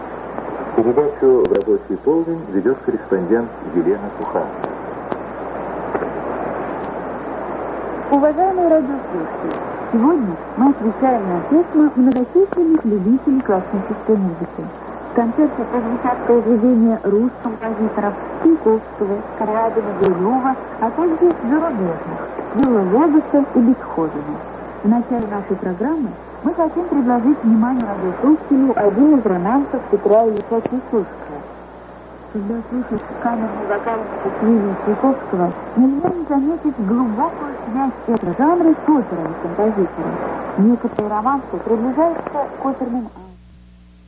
Длительность: 44 с, битрейт: 61 Кбит/с, размер файла: 330 КБ) Описание изображения Описание Запись эфира передачи «В рабочий полдень».